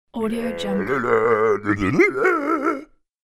دانلود افکت صوتی کارتون: آواز خواندن انسان اولیه (مرد غارنشین) با خوشحالی